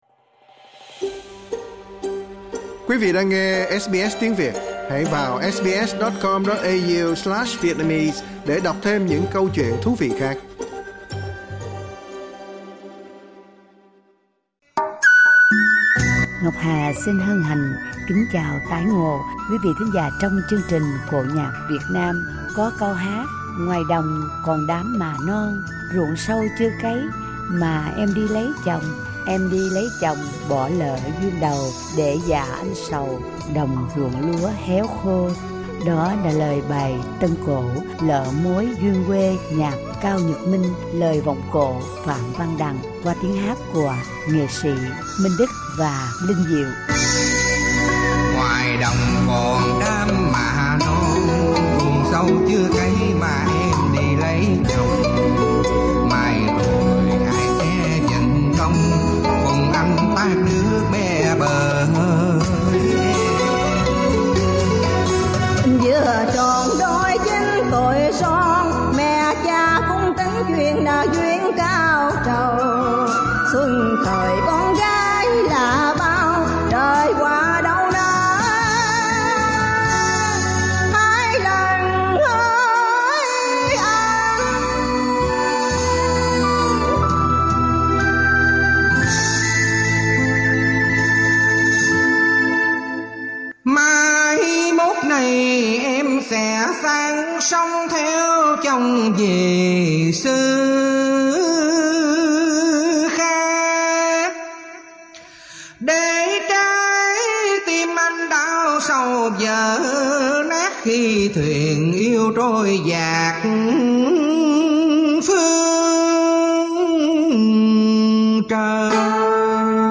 bài tân cổ